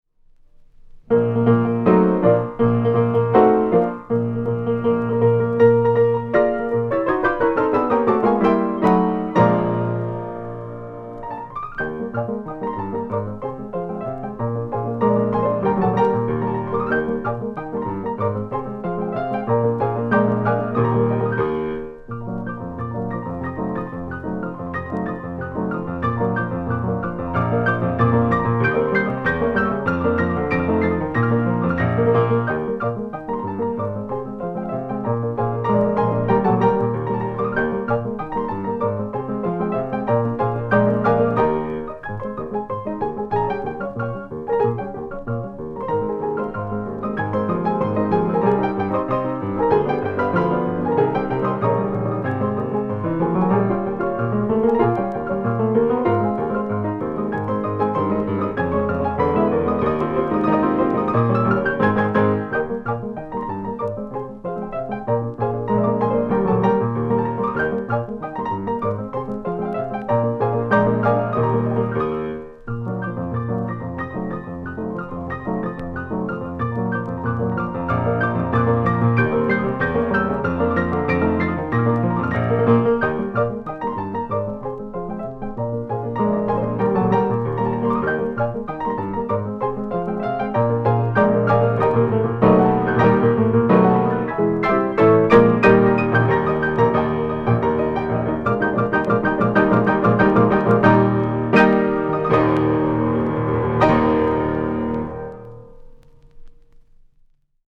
Sarau de Sinhá – Recitativo